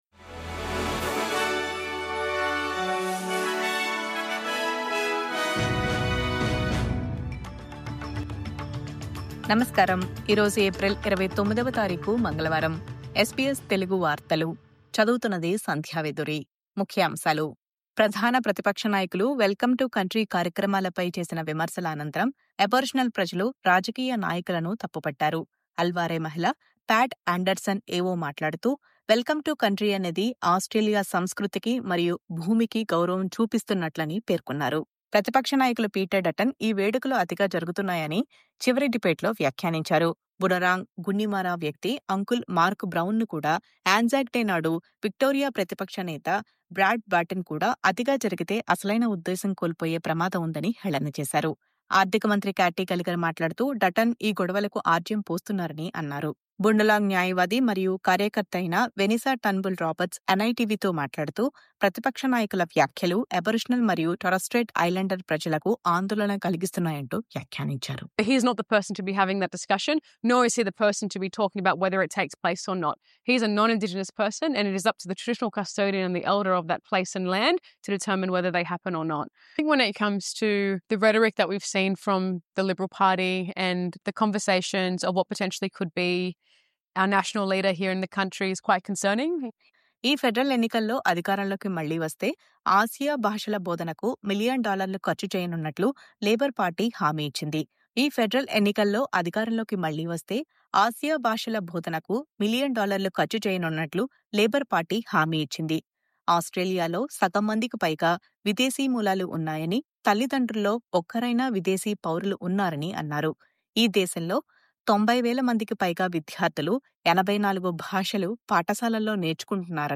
SBS తెలుగు వార్తలు.